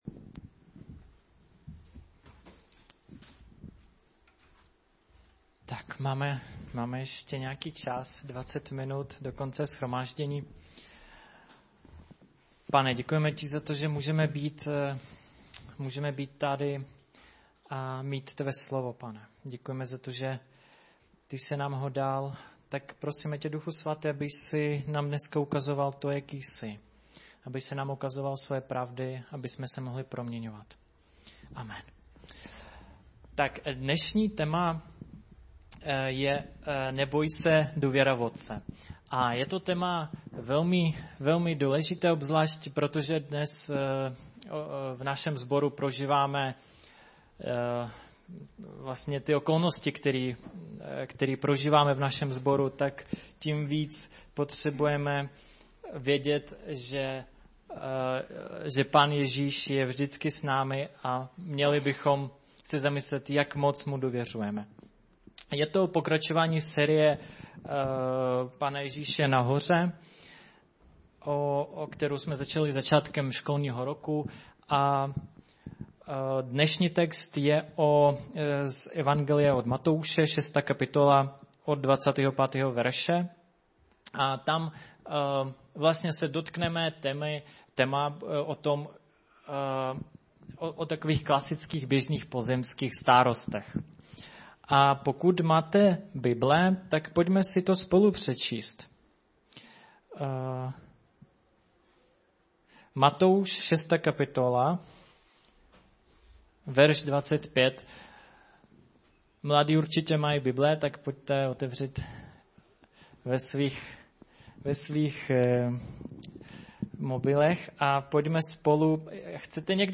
Kázání - Strana 72 z 220 - KS Praha